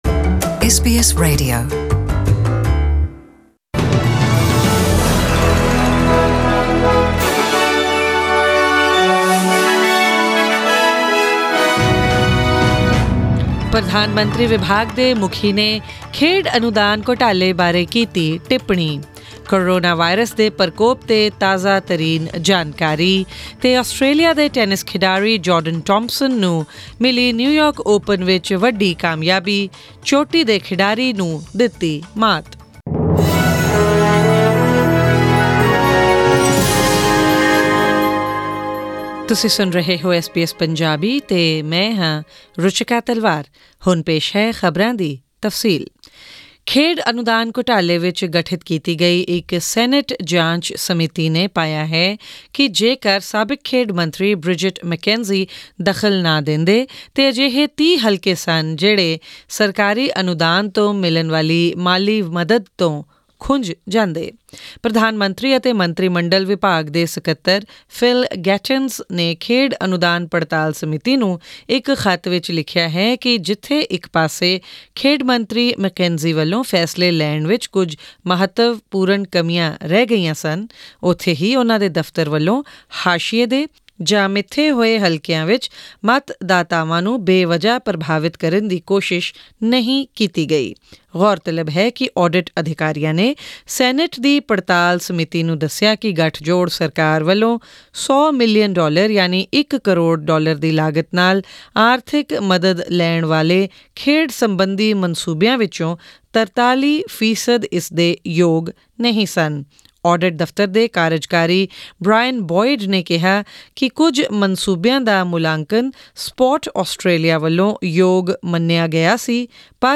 Australian News in Punjabi: 14 February 2020